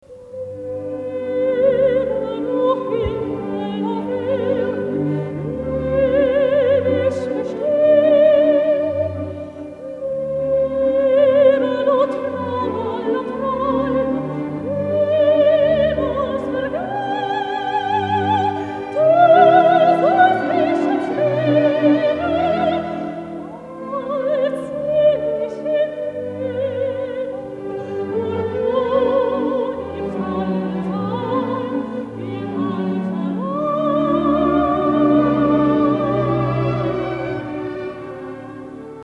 Operette